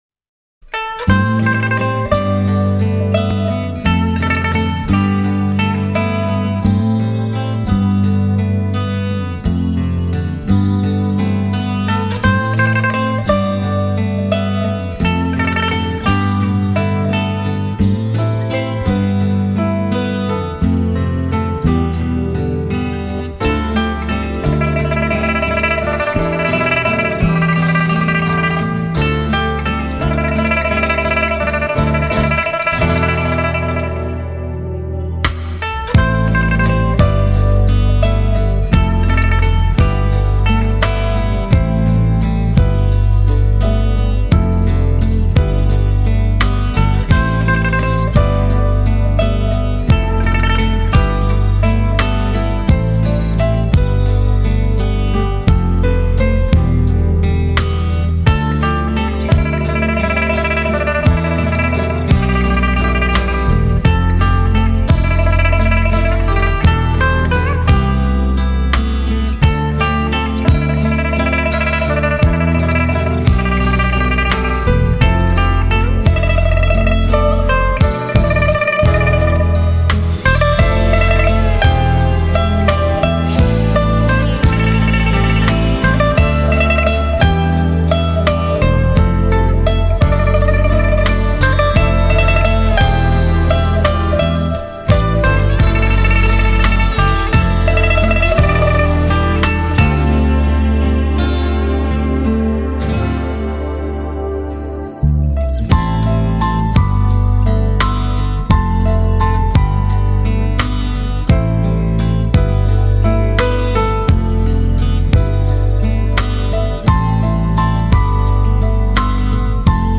音乐分类: 古典音乐